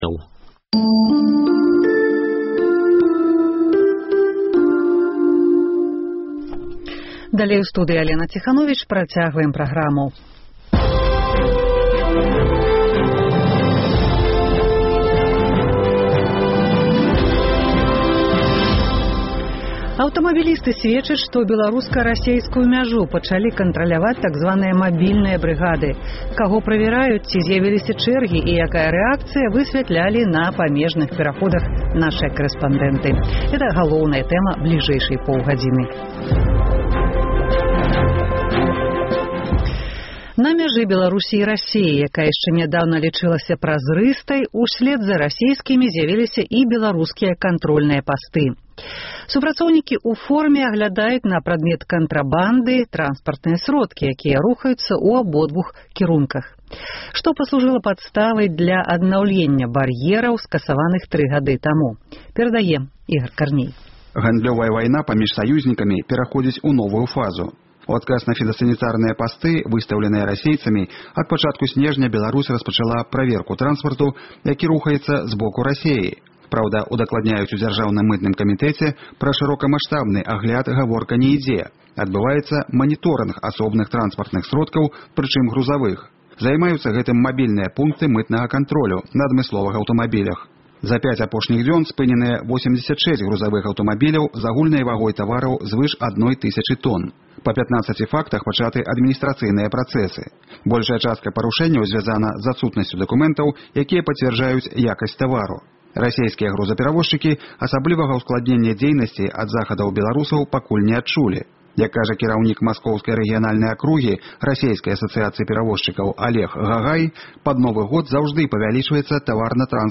Аўтамабілісты сьведчаць, што беларуска-расейскую мяжу пачалі кантраляваць так званыя «мабільныя брыгады». Каго правяраюць, ці зьявіліся чэргі, якая рэакцыя — высьвятлялі на памежных пераходах нашыя карэспандэнты.